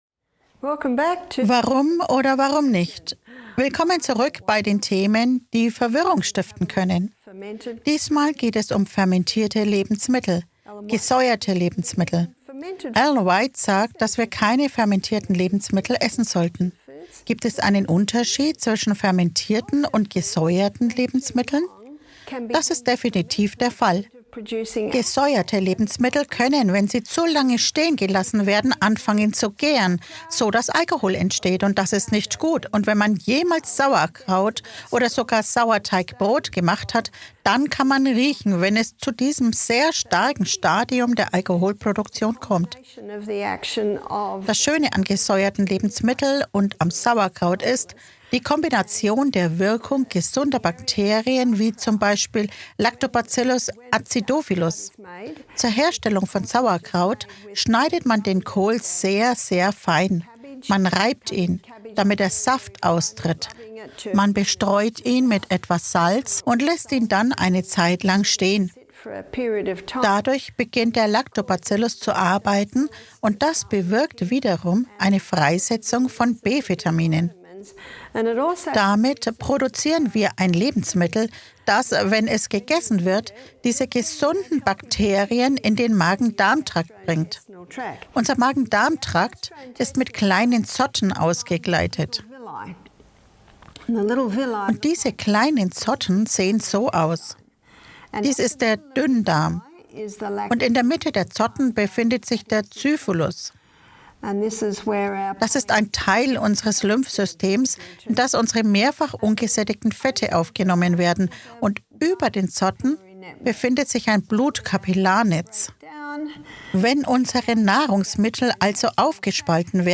Entdecken Sie die Kraft der gesäuerten Lebensmittel und deren positiven Einfluss auf die Gesundheit! Dieser spannende Vortrag revolutioniert das Wissen über Darmflora und gesunde Bakterien wie Lactobacillus acidophilus.